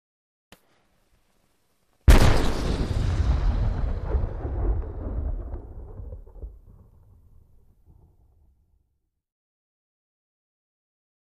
Large Single Cannon Fire with Fuse; Soft Flare Up Of Fuse Followed By Large Cannon Blast And Long Echo. Medium Close Perspective.